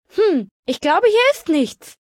Maleuniquebiwwy_genericcus_alerttonormal_0006fe49.ogg (OGG-Mediendatei, Dateigröße: 18 KB.
Fallout 3: Audiodialoge